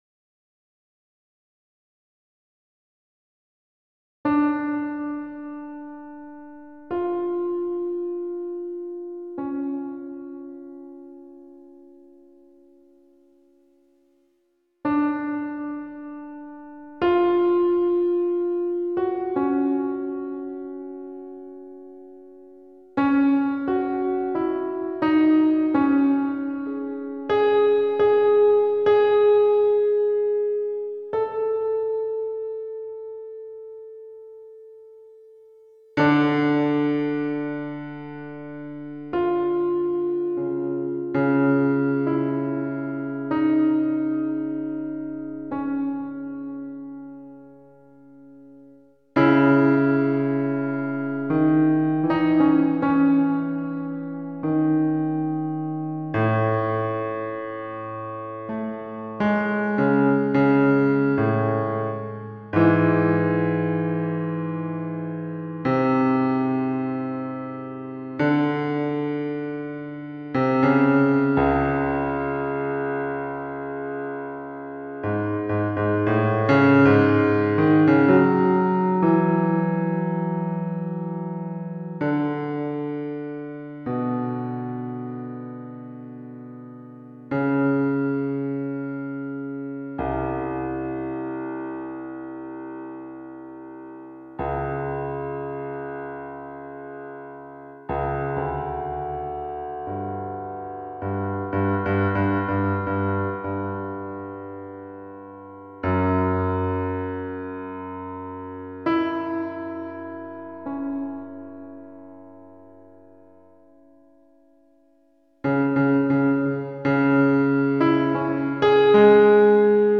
Posted in Classical, Piano Pieces Comments Off on